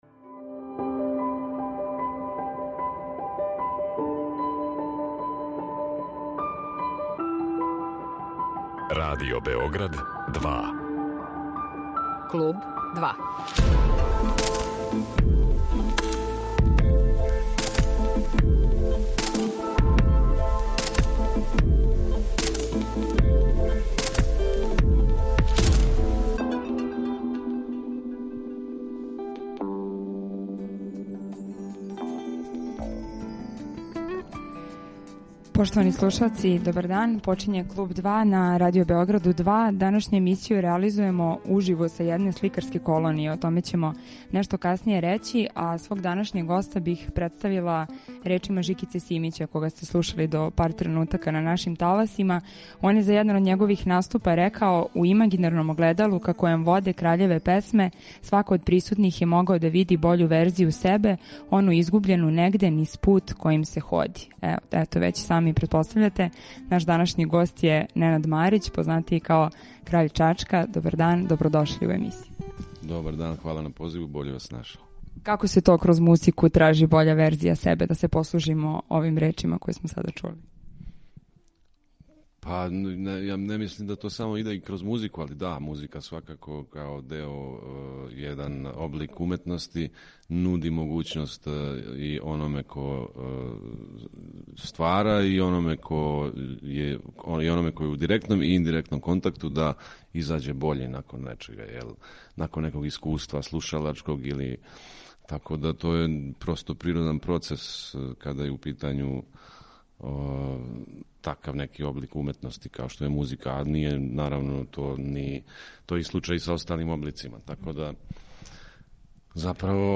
Идеални простори за стварање, платна препуна људи која значе самоћу, сусрети, светови у којима „јава постају сни", концерти који изазивају катарзична расположења - само су неке од тема о којима ћемо разговарати са Краљем Чачка, кога затичемо на једној сликарској колонији са које уживо реализујемо емисију.